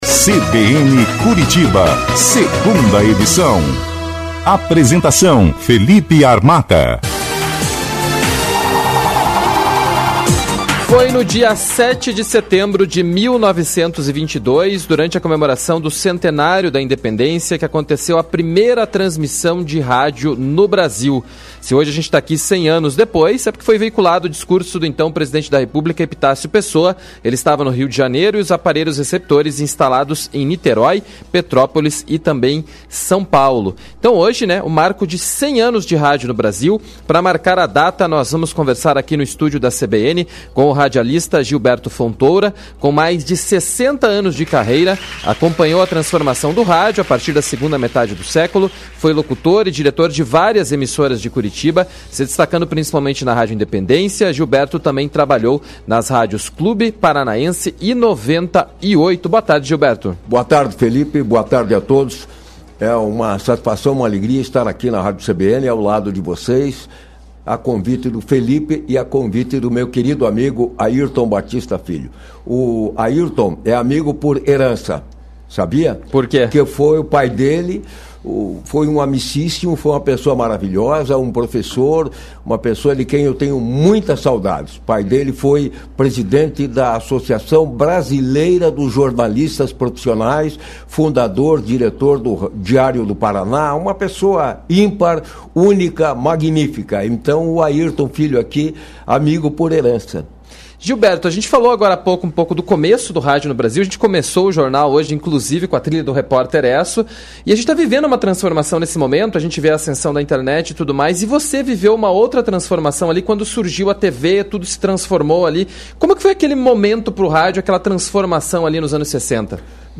ENTREVISTA-100-ANOS-RADIO.mp3